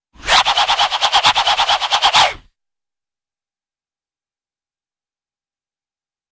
Звук с эффектом бумеранга